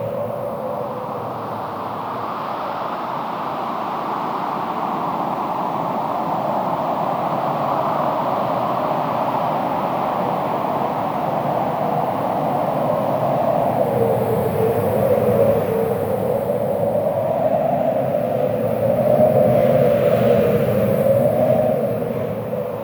Viento.wav